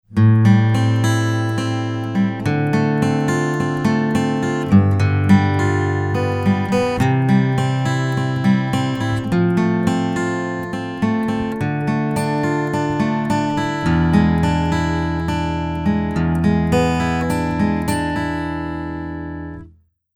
Звук Акустики
K&K xlr preamp для акустики. Запись в линию. Пьезодатчик пассивный.
Обработка: только ревер, без мастеринга.
Струны не первой свежести, не помню точно, думаю несколько месяцев.